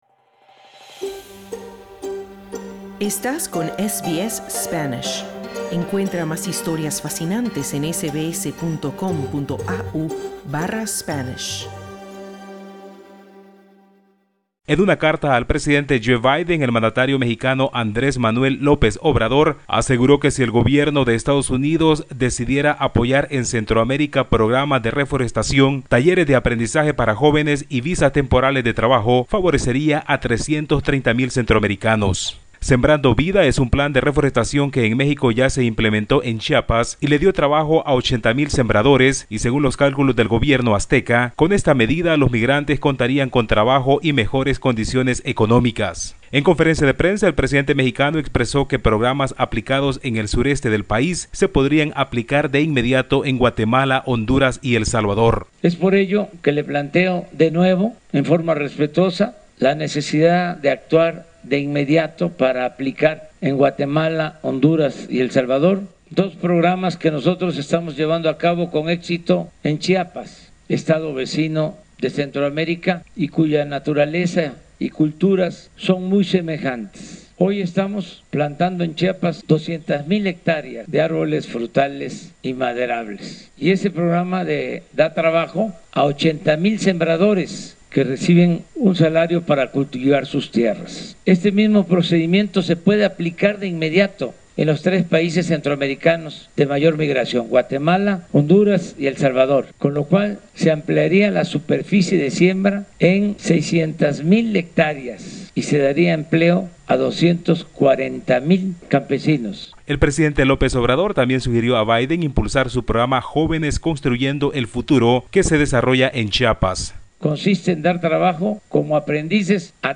Escucha el informe del corresponsal de SBS Spanish en…